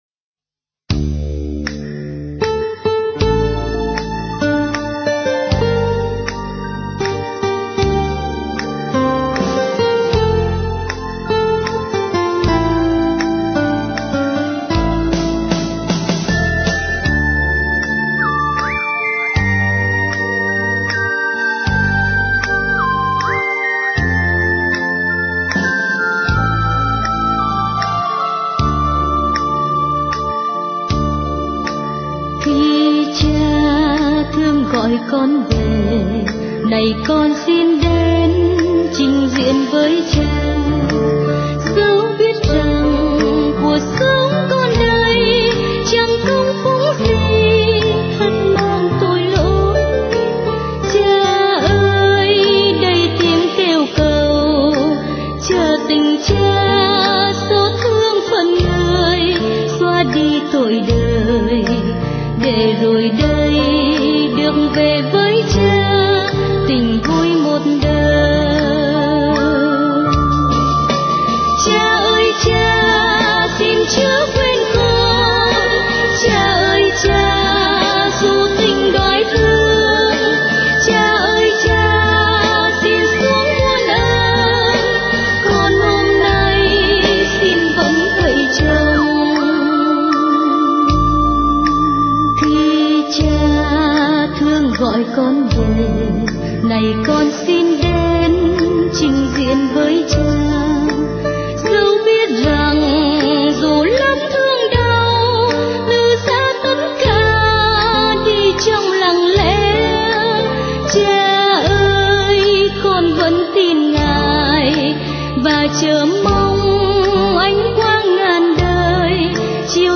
* Thể loại: Cầu hồn